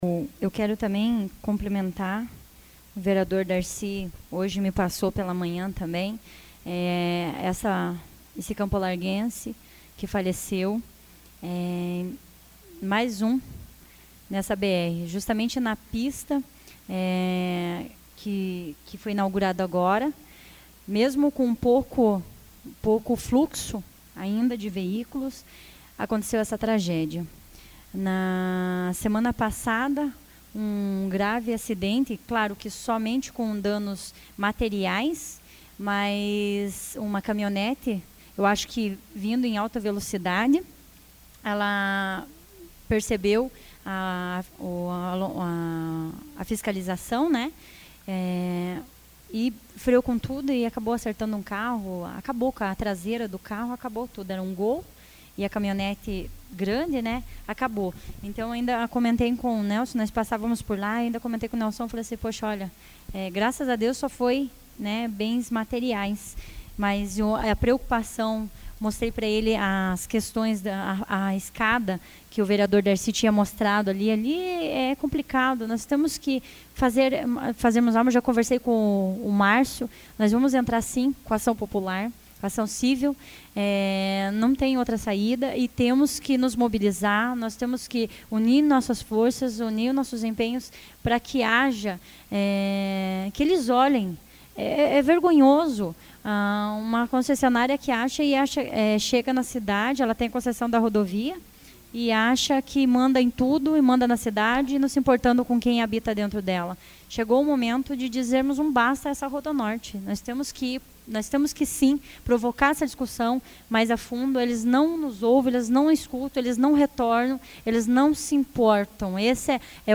Explicação pessoal AVULSO 25/02/2014 Fernanda Queiroz